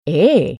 INTONAZIONI & ACCENTI DI PAROLA
Extra basso